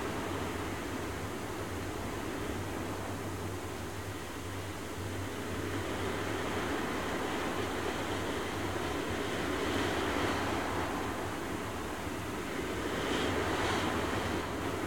WindLight2.ogg